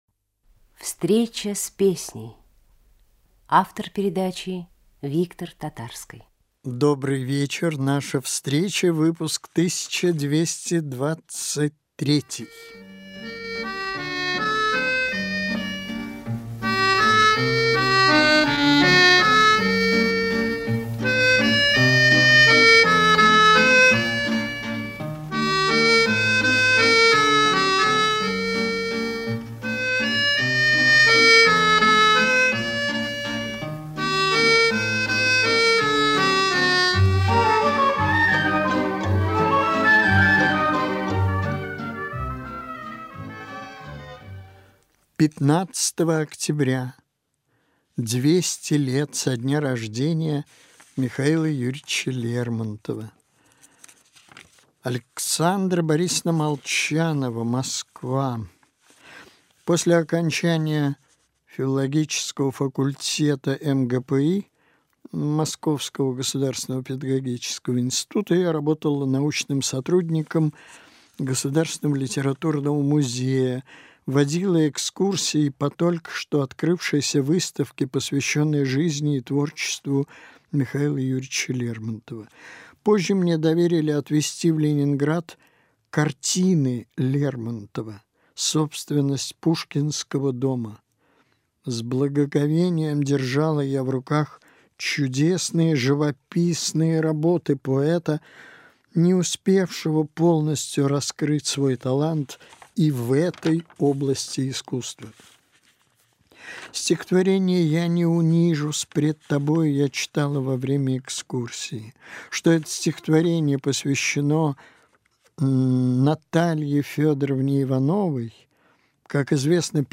(романс)
Женская группа уральского народного хора
(русская песня)
(Foxtrott)
Вёл "Встречу" - Виктор Татарский